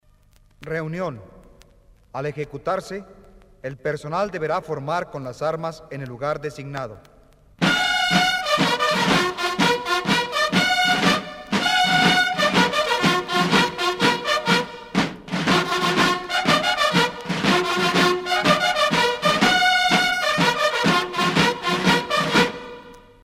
TOQUES MILITARES REGLAMENTARIOS EN MP3.
reunion.mp3